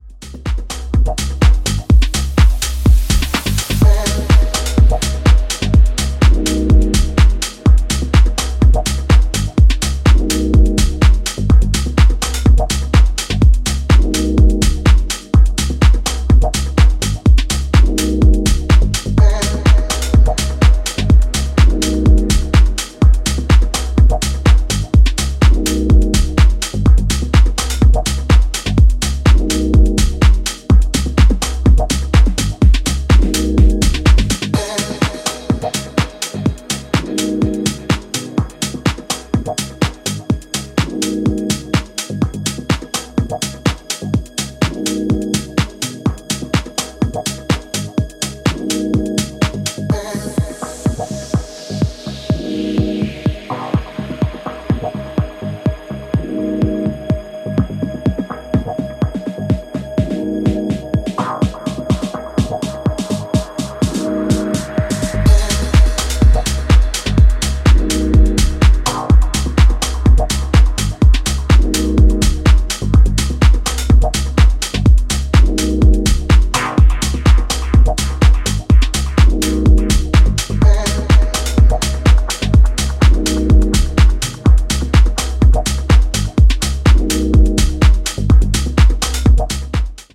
パッドのアクアティックな余韻がフロアを包む
全曲端正に作り込まれたミニマル・テック・ハウスに仕上がっています！